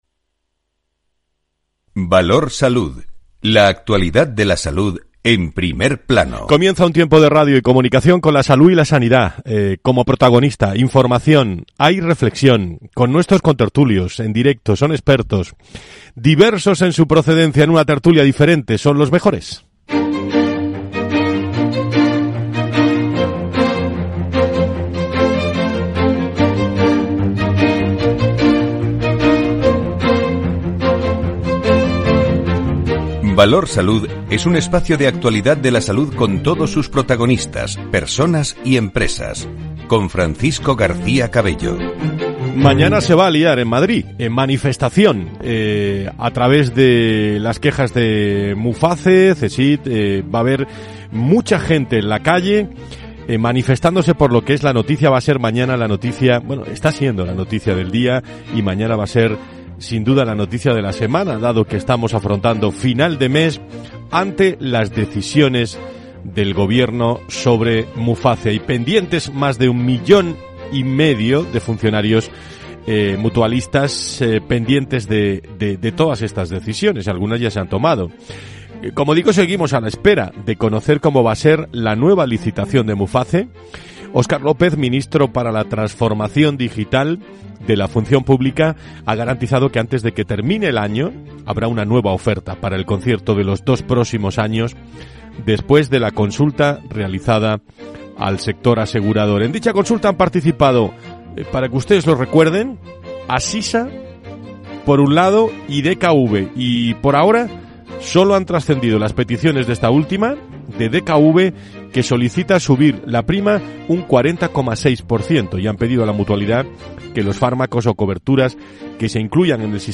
Un programa en directo diario dónde puedes aprender y preguntar sobre finanzas personales y mercados financieros.